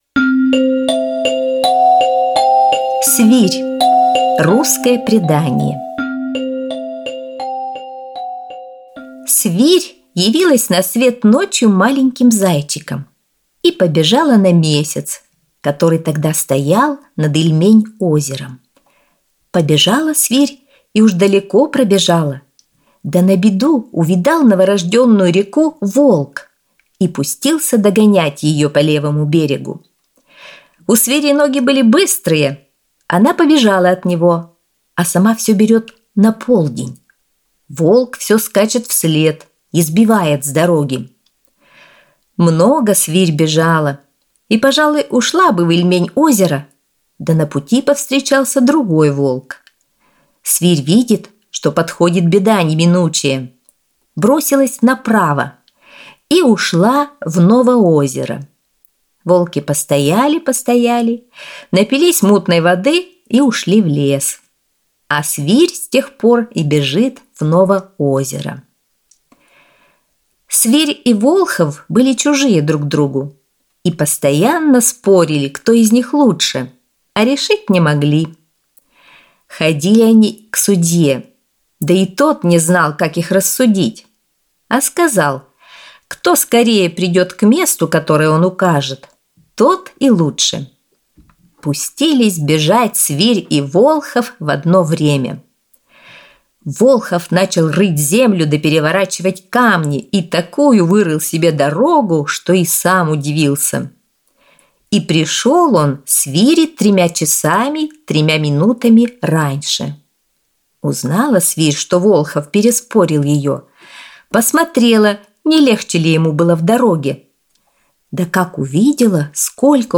Аудиосказка «Свирь»